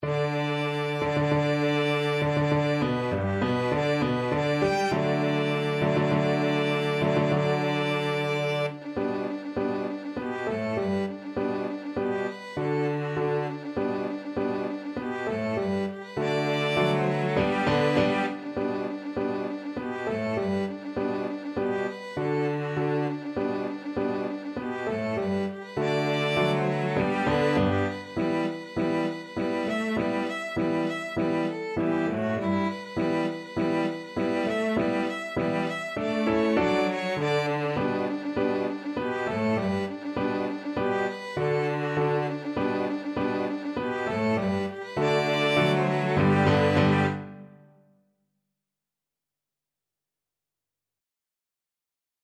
G major (Sounding Pitch) (View more G major Music for Violin-Cello Duet )
4/4 (View more 4/4 Music)
Presto =200 (View more music marked Presto)
Violin-Cello Duet  (View more Easy Violin-Cello Duet Music)
Classical (View more Classical Violin-Cello Duet Music)